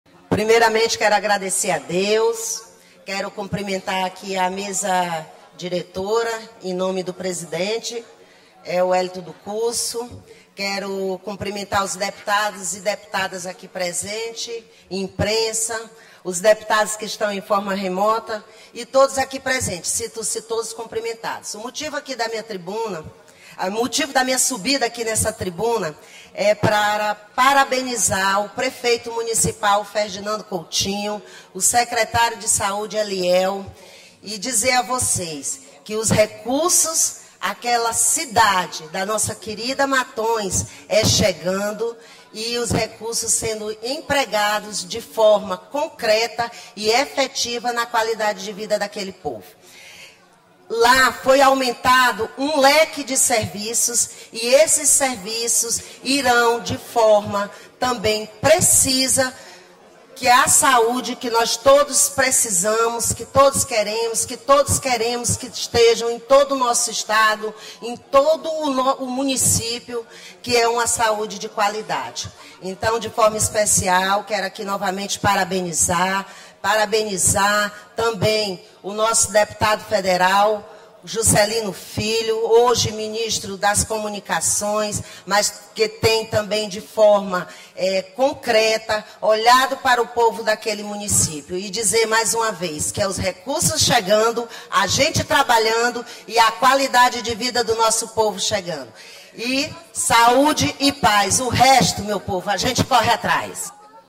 Início -> Discursos